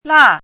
注音： ㄌㄚˋ
la4.mp3